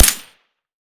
WPN_Pistol10mm_Fire_Silenced_Player_01.wav